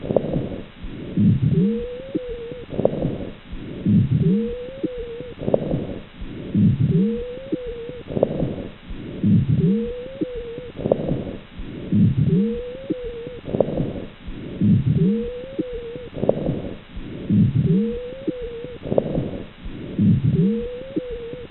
HENGITYSÄÄNET
Toinen näyte on taltioitu oikeasta kyljestä.
Jälkimmäisessä näytteessä kuuluva ekspiratorinen vinkuna sopii potilaan sairastamaan astmaan.